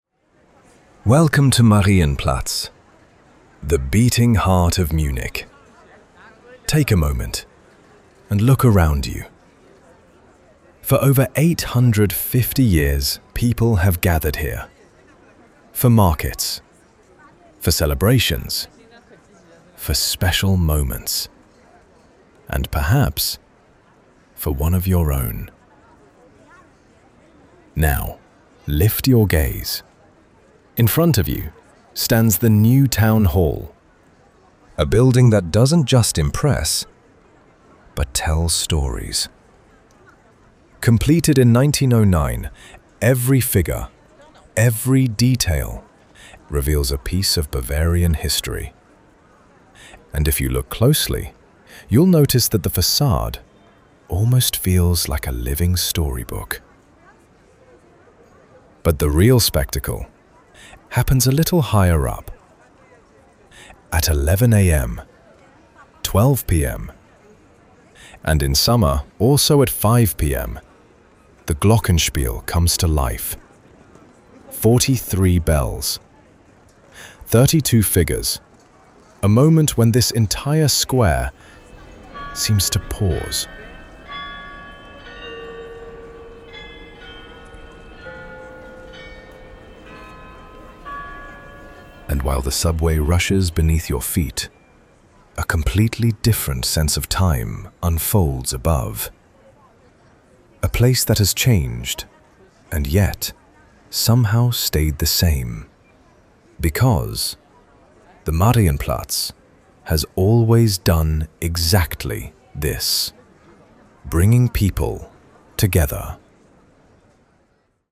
Audiofeature · Flagship
The highlight is the legendary Glockenspiel – first described in detail, then heard in the original.
Original concept and script as a cinematic audio feature with clear dramaturgical structure, deliberate pauses, changes in pace, and the original Glockenspiel as the acoustic centrepiece.
V3 ElevenLabsScript CreationSound DesignSound FX